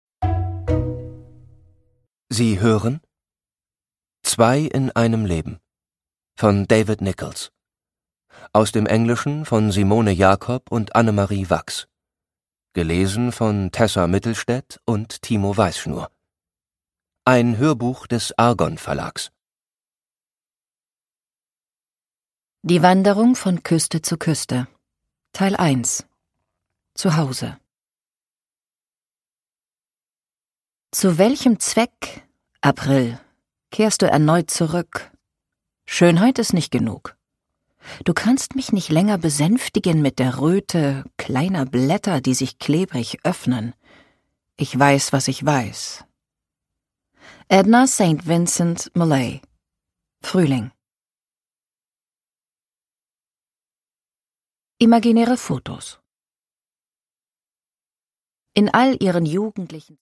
David Nicholls: Zwei in einem Leben (Ungekürzte Lesung)
Produkttyp: Hörbuch-Download